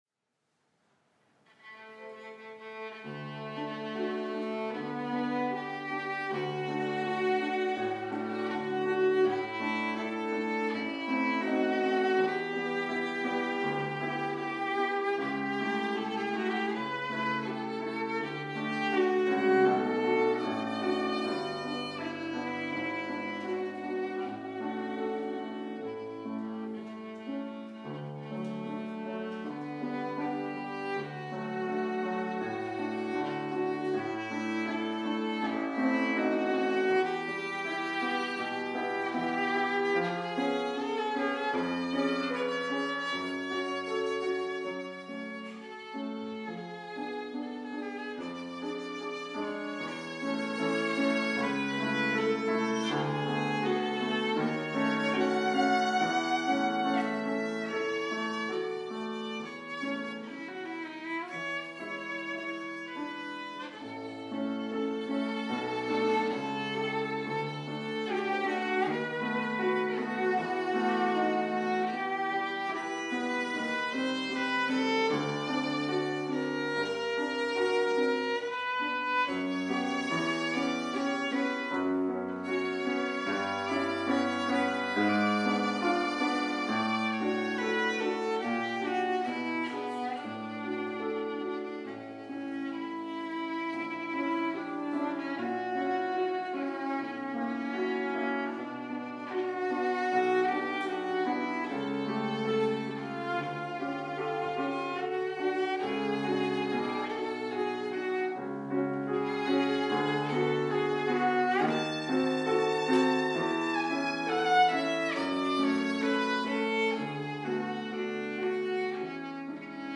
音楽発表会
私もビオラで参加することができました。
バイオリンの曲をビオラでも演奏できるように編曲された楽譜を使いました。レッスンを一度受け、前々日にピアノ合わせをしただけでしたが、木のホールの響きに助けられ、素晴らしいピアノ伴奏のもとで気持ちよく演奏することができました。ベーゼンドルファーのピアノは奥深くて優しい音色でした。